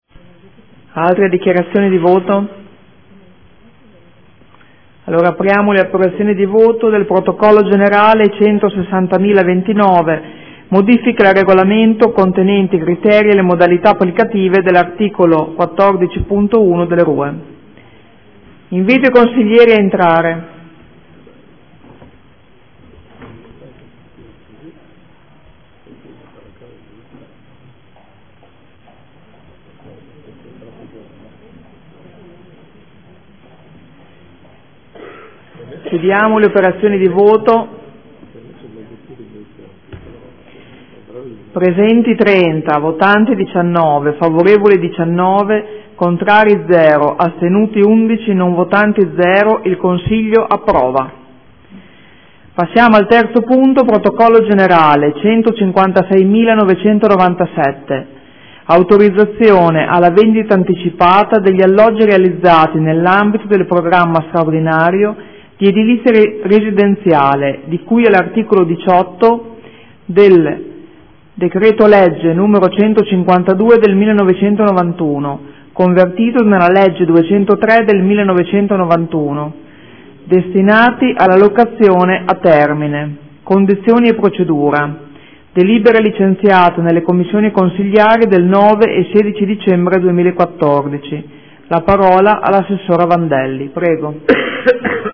Presidente — Sito Audio Consiglio Comunale
Seduta del 18/12/2014 Mette ai voti. Modifiche al Regolamento contenente i criteri e le modalità applicative dell’articolo 14.1 del RUE.